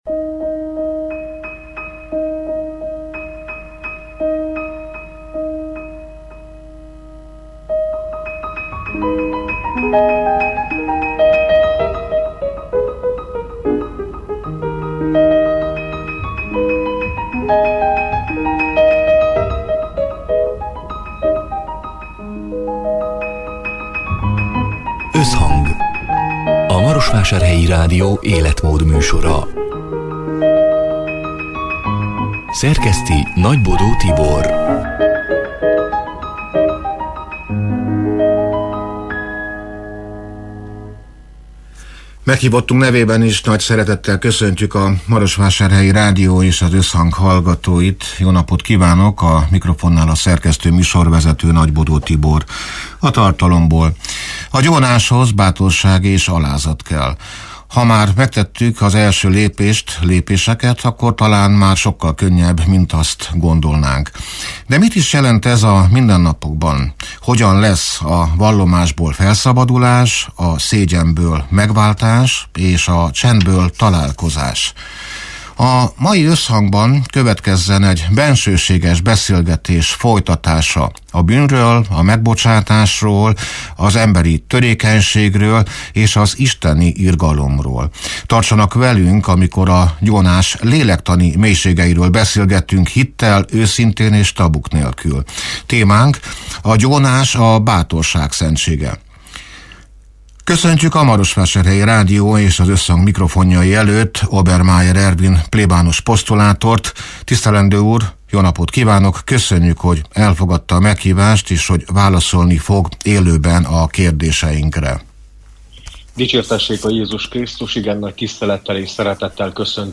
(elhangzott: 2025. július 2-án, szerdán délután hat órától élőben)
Hogyan lesz a vallomásból felszabadulás, a szégyenből megváltás, a csendből találkozás? A soron következő Összhangban egy bensőséges beszélgetés következik a bűnről, a megbocsátásról, az emberi törékenységről és az isteni irgalomról.